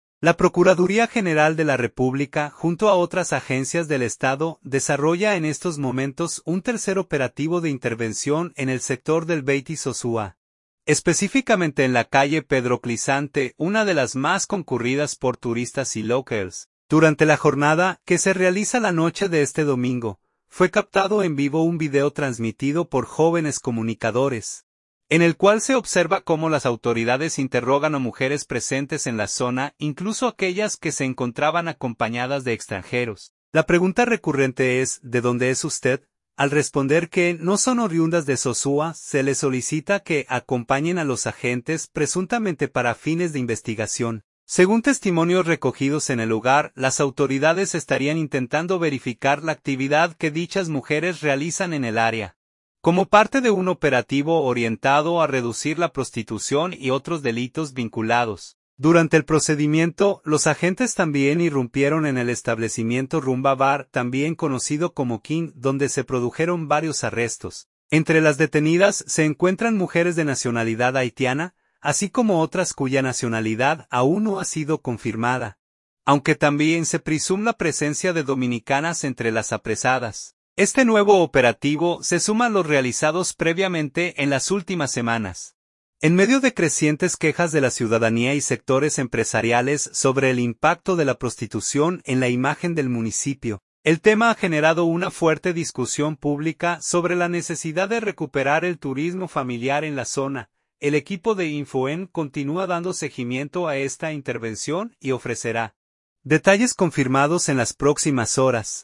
Durante la jornada, que se realiza la noche de este domingo, fue captado en vivo un video transmitido por jóvenes comunicadores, en el cual se observa cómo las autoridades interrogan a mujeres presentes en la zona, incluso aquellas que se encontraban acompañadas de extranjeros.